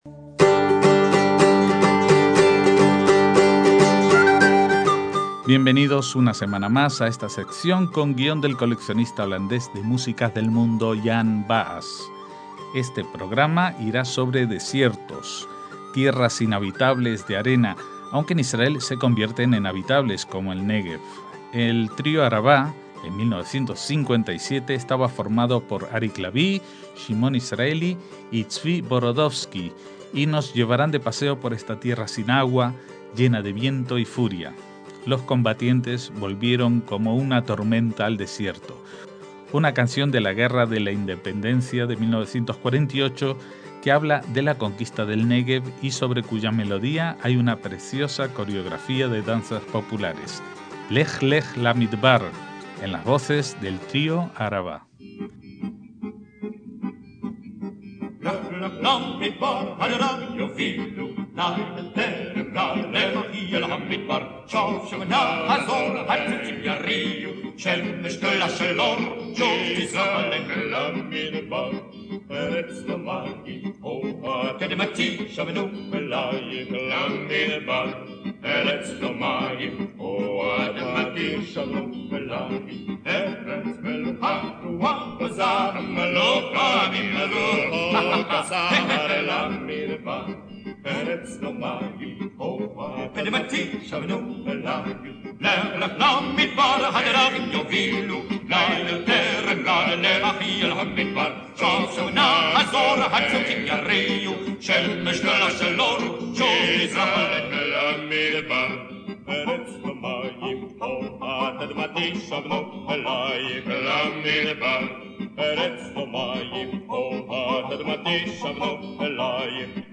Radio Sefarad